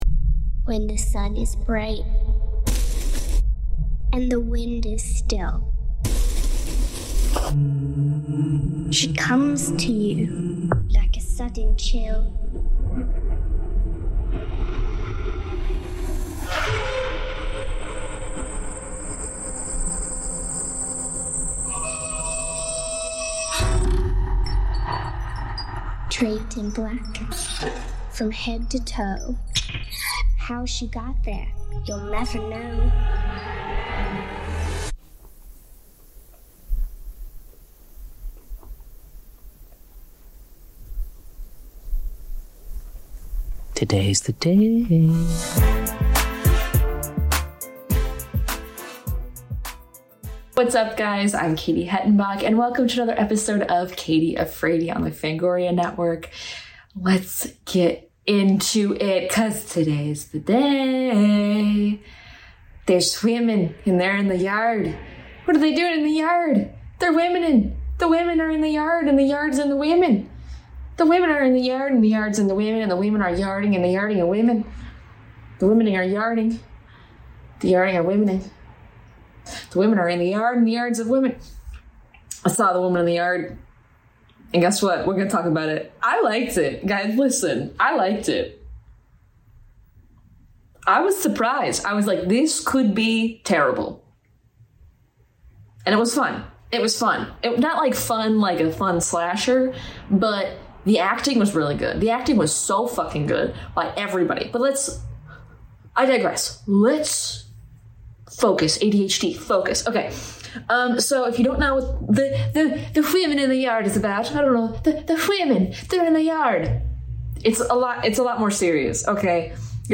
a horror movie review podcast
talks with comedians, actors, and filmmakers about horror movies!